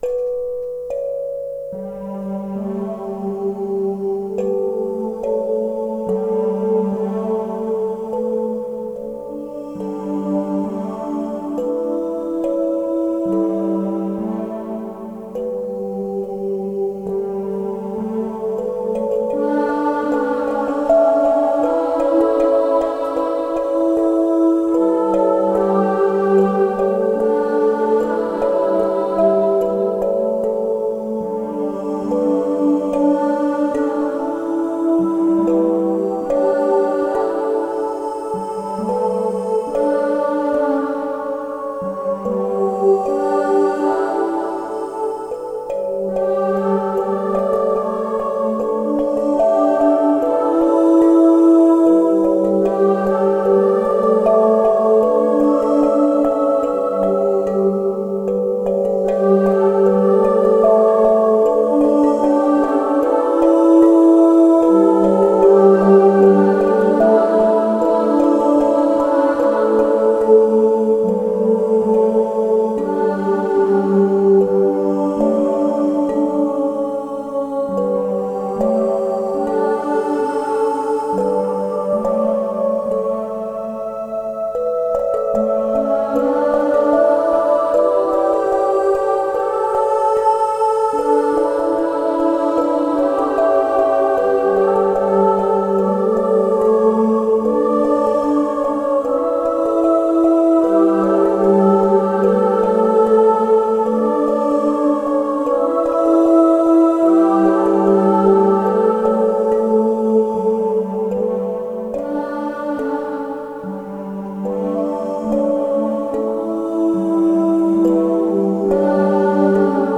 Pan, Folk and Vocals relaxed.